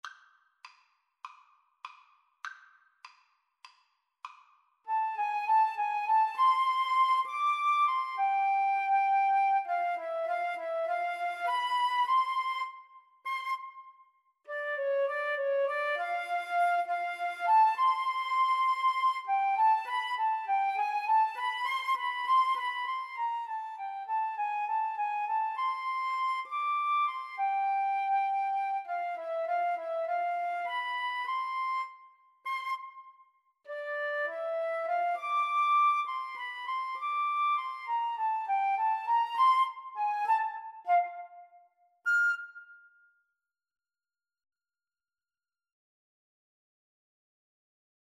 FluteAlto Saxophone
A light-hearted Ragtime-style piece.
4/4 (View more 4/4 Music)
Jazz (View more Jazz Flute-Saxophone Duet Music)